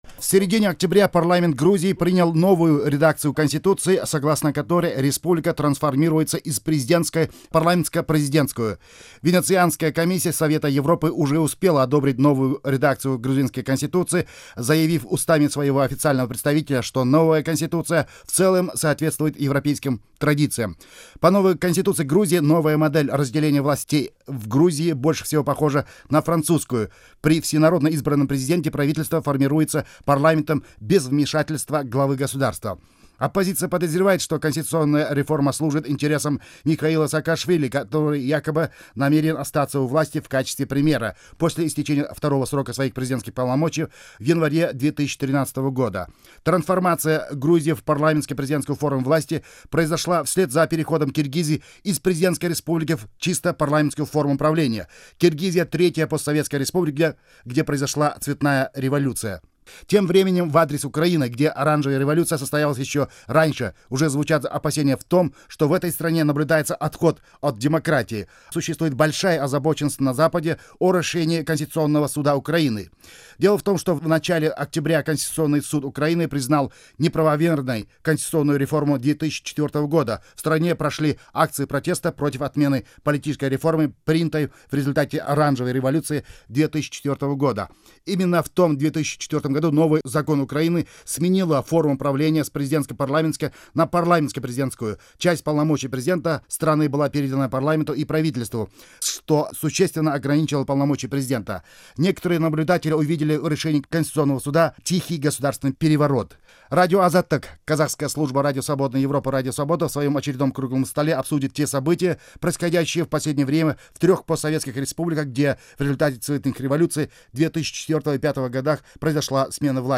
Запись Круглого стола